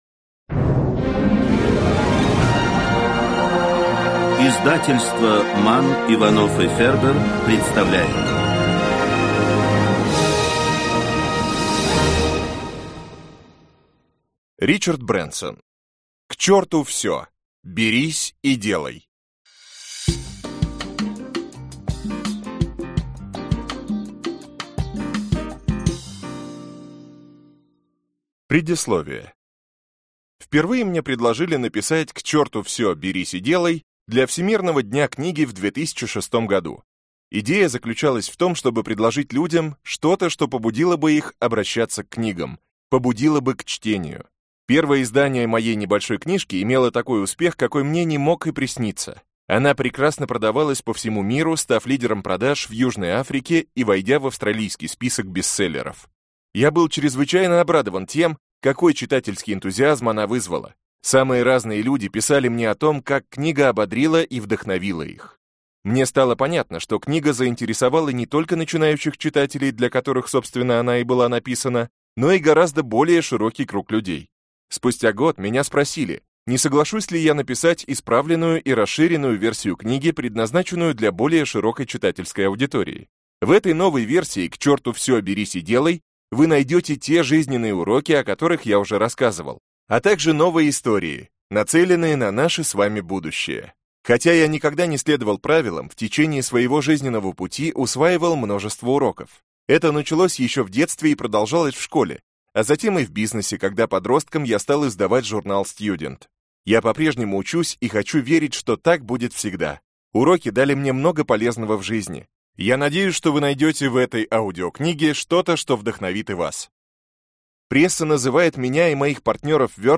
Аудиокнига К черту всё! Берись и делай! - купить, скачать и слушать онлайн | КнигоПоиск